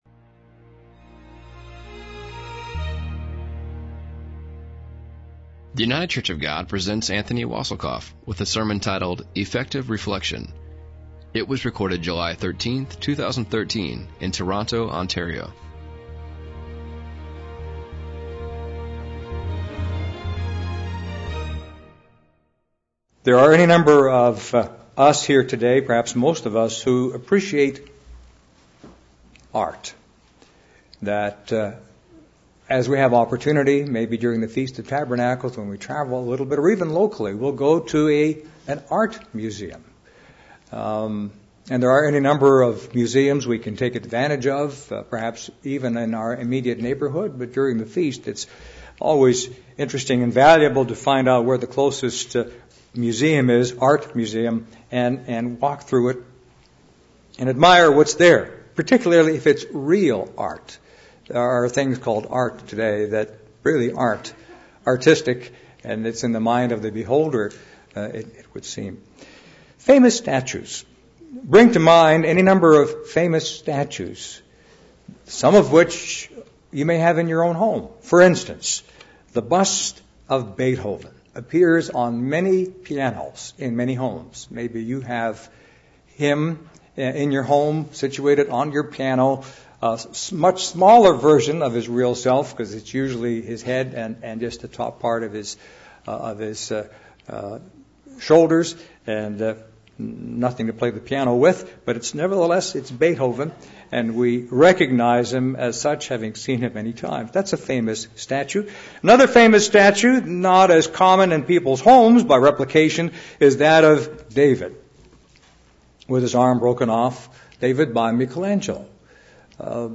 It was recorded July 13, 2013, in Toronto, Ontario.